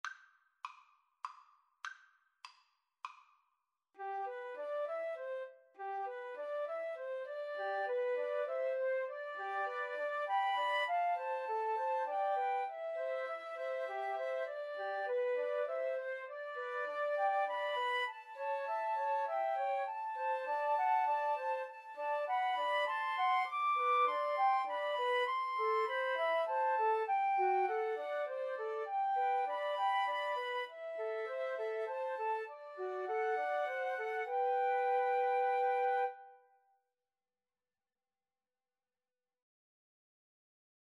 Play (or use space bar on your keyboard) Pause Music Playalong - Player 1 Accompaniment Playalong - Player 3 Accompaniment reset tempo print settings full screen
17th-century English folk song.
G major (Sounding Pitch) (View more G major Music for Flute Trio )
3/4 (View more 3/4 Music)
Moderato